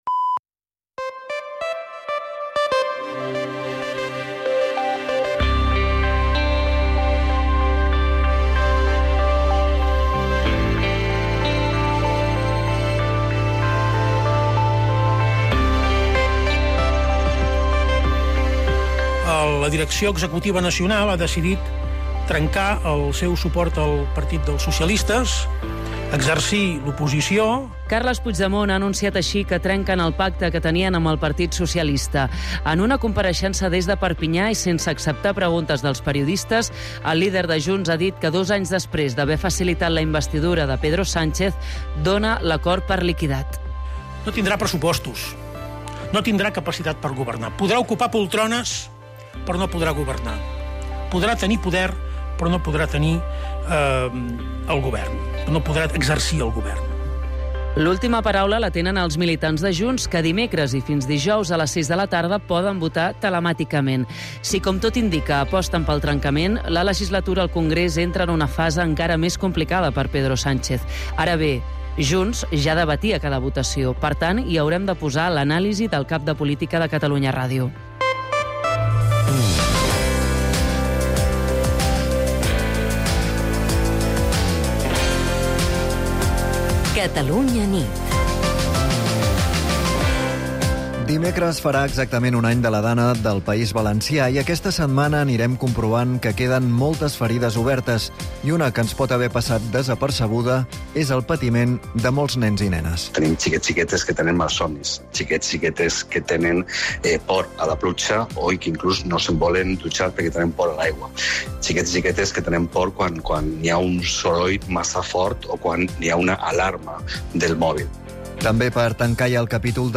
El compromís d'explicar tot el que passa i, sobretot, per què passa és la principal divisa del "Catalunya nit", l'informatiu nocturn de Catalunya Ràdio, dirigit per Manel Alías i Agnès Marquès.